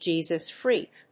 アクセントJésus frèak